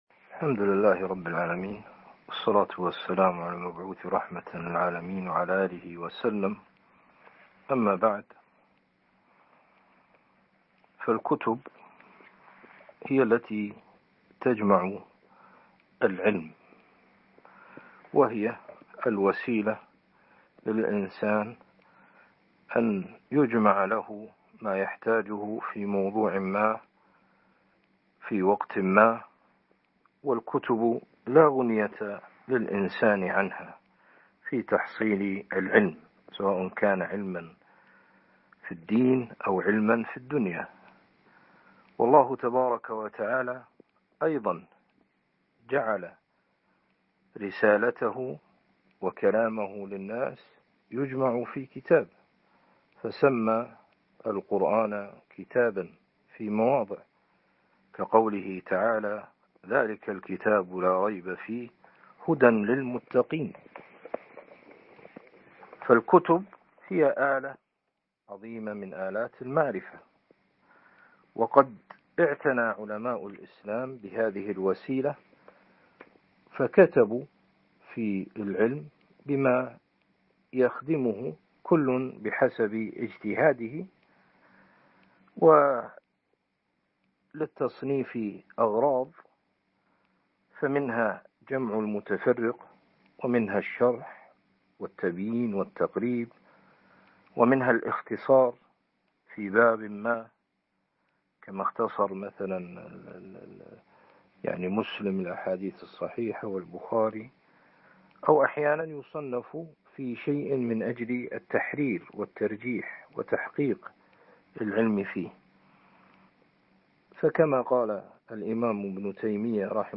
تعريف بكتاب - الدرس الأول ( كتاب العقيدة الواسطية لشيخ الإسلام ابن تيمية )